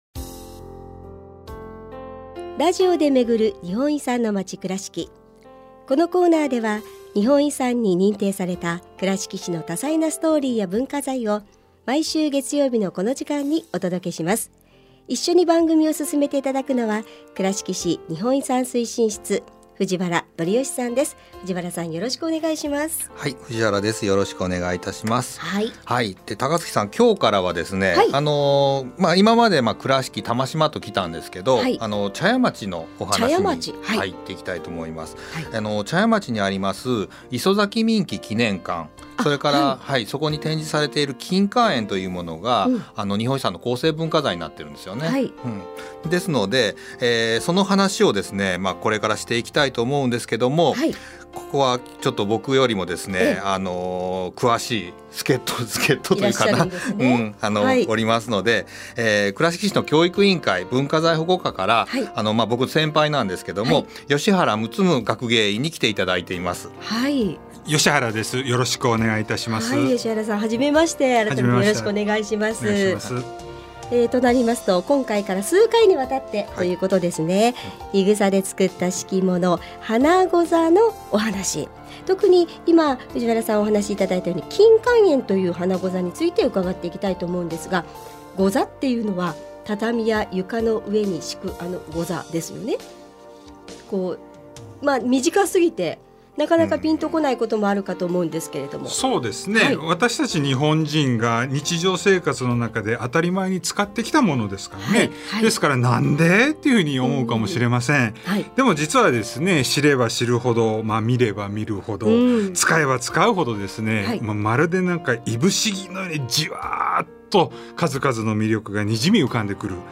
平成31年4月～令和2年3月の間、FMくらしきのワイド番組「モーニングくらしき」内で、倉敷市の日本遺産を紹介するコーナー「ラジオで巡る日本遺産のまち くらしき」を放送しました。